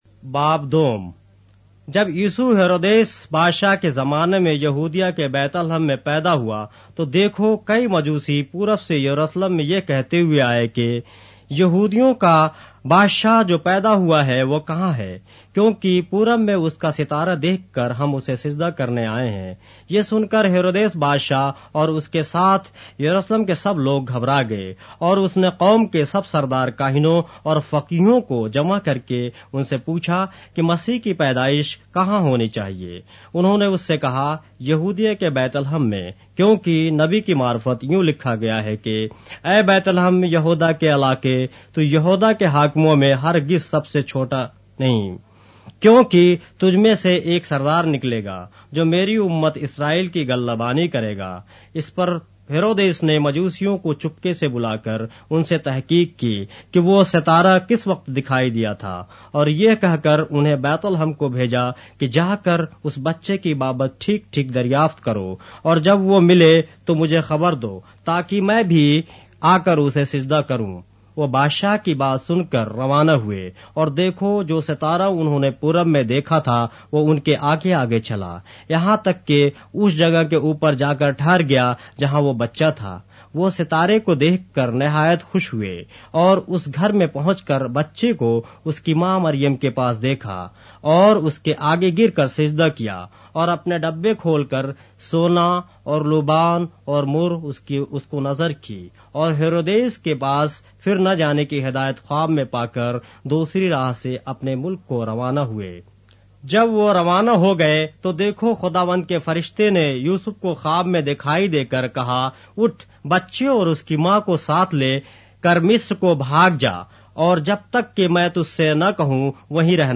اردو بائبل کے باب - آڈیو روایت کے ساتھ - Matthew, chapter 2 of the Holy Bible in Urdu